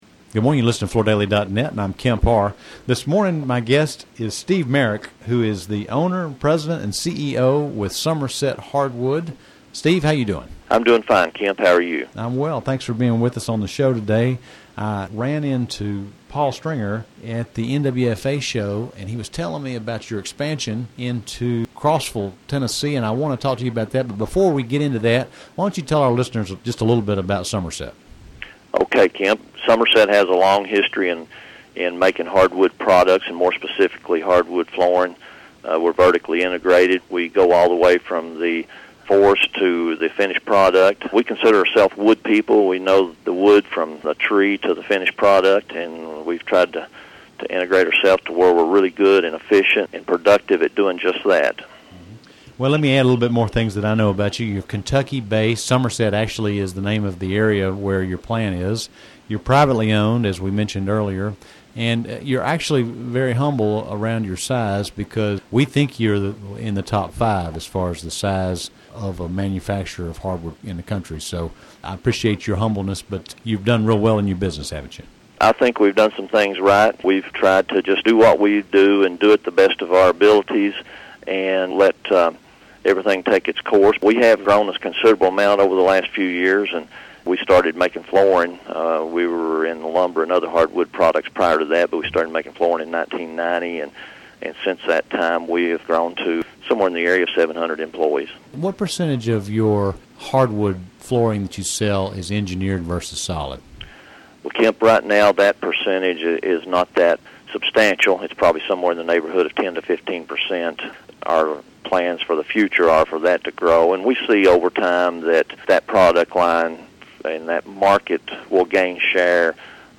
Listen to the interview to hear more details about Somerset and its expansion.